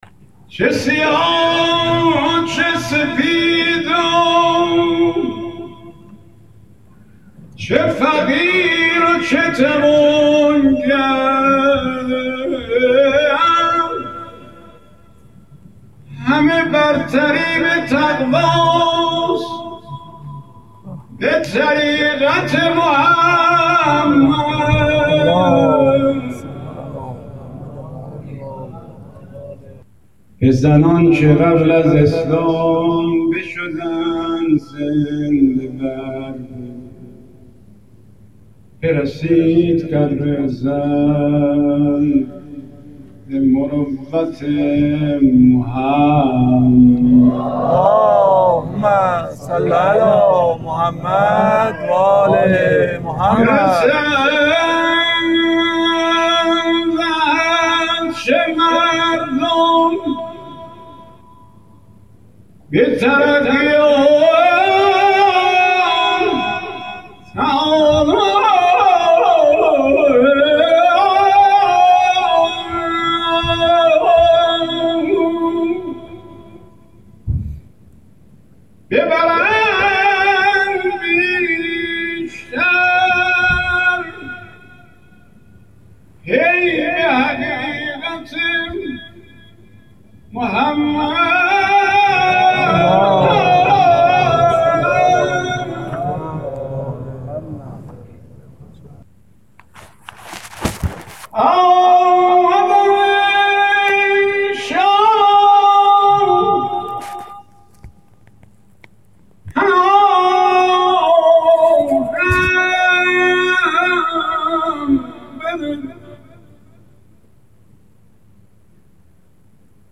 در نخستین آئین نکوداشت چهره‌های برتر روزنامه‌نگاری انقلاب اسلامی
با صوت زیبای خود